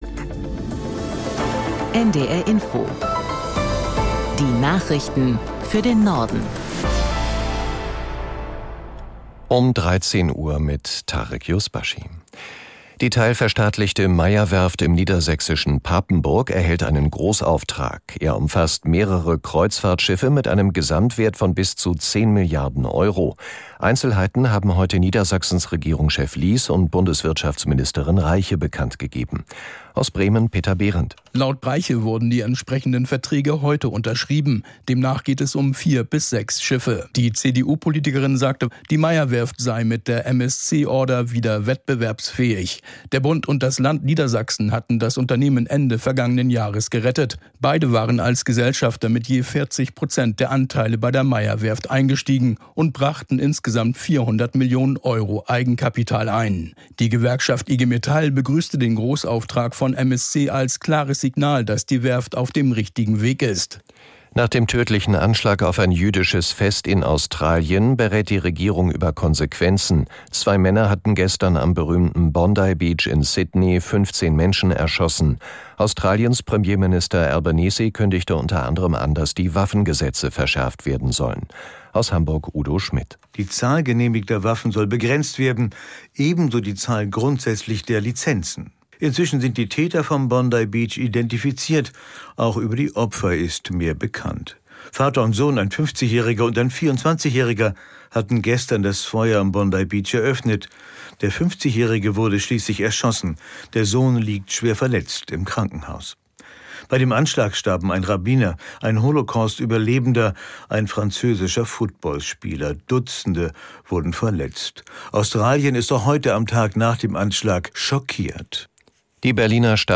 Die aktuellen Meldungen aus der NDR Info Nachrichtenredaktion.